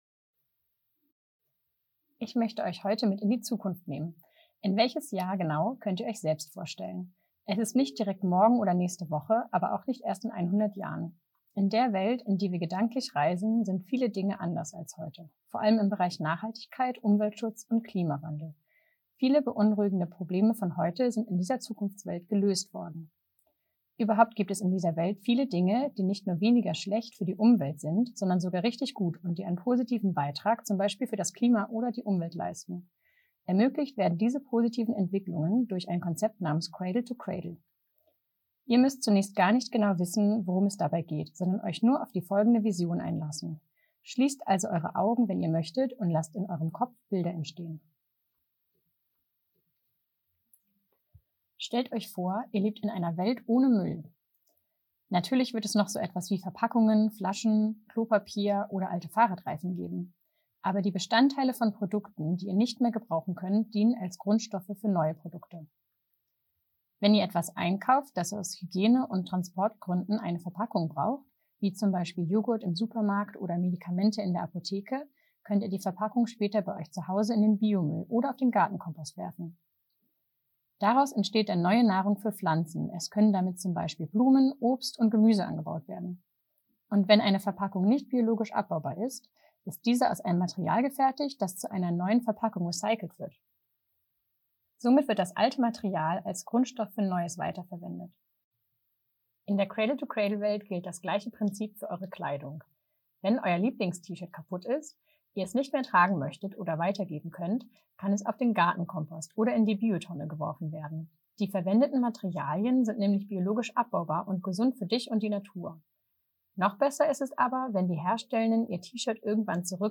Primarily a (pre-)reading text describing a C2C world with its different areas. Listeners travel along in their thoughts.